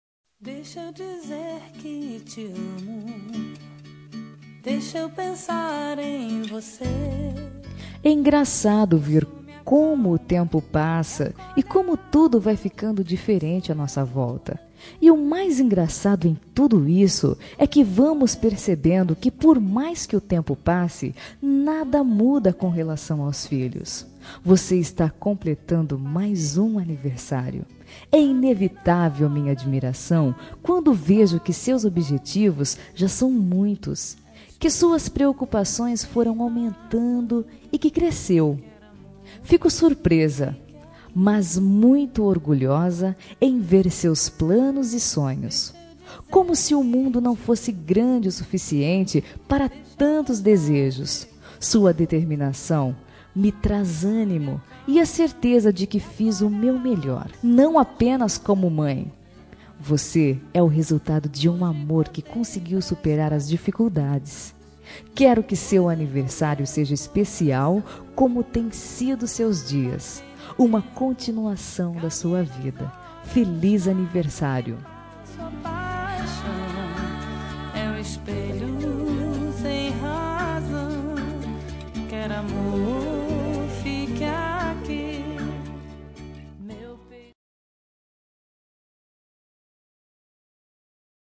Telemensagem de Aniversário de Filha – Voz Masculina – Cód: 1791 – Bonita